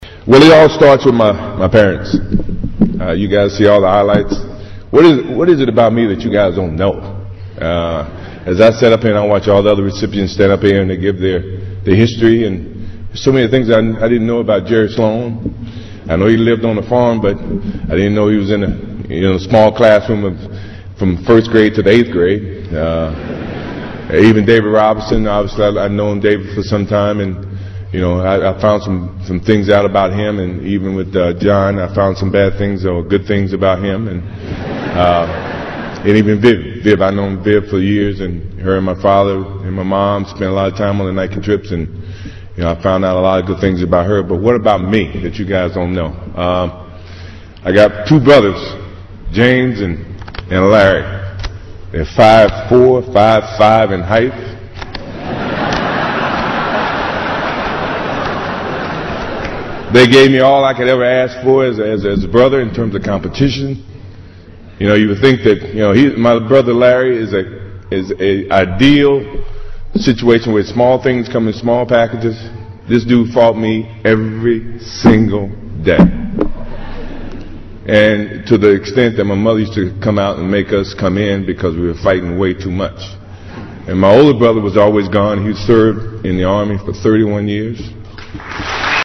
在线英语听力室偶像励志英语演讲 第68期:不说绝不(2)的听力文件下载,《偶像励志演讲》收录了娱乐圈明星们的励志演讲。